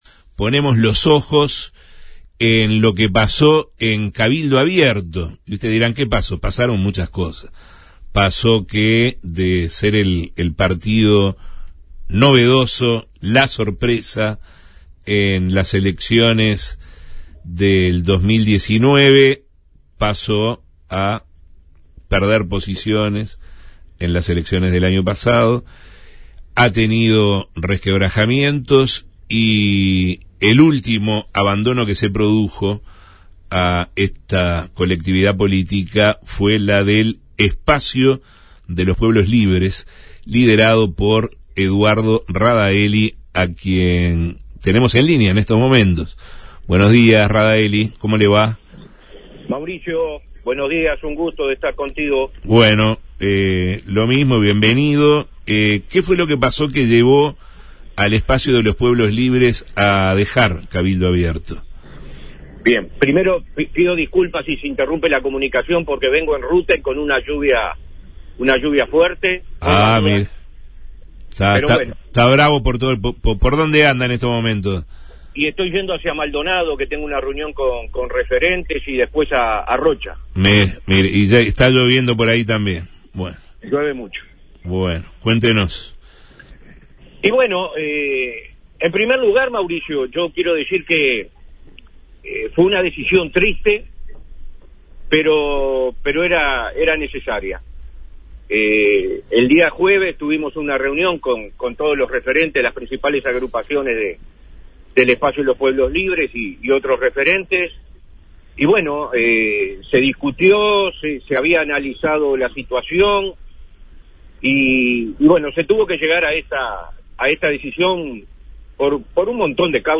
El líder del Espacio de los Pueblos Libres, Eduardo Radaelli, comentó en Justos y pecadores la causas que lo alejaron de Cabildo Abierto